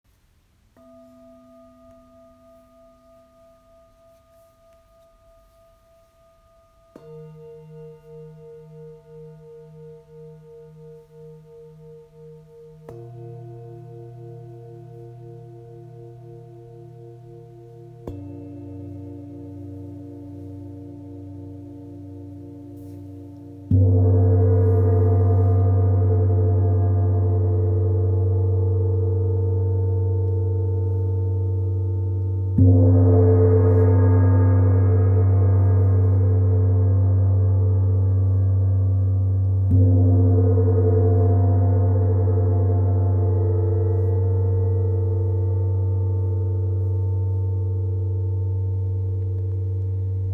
(Bols tibétains et Fen-Gong pour massage sonore)
La plupart des instruments est accordée à 432 hertz, fréquence particulièrement au diapason de notre être profond.
Bols-the_rapeutique-etGong.m4a